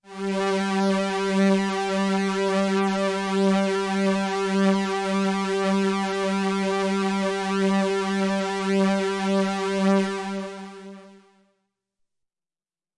标签： MIDI-速度-96 FSharp4 MIDI音符-67 罗兰-JX-3P 合成器 单票据 多重采样
声道立体声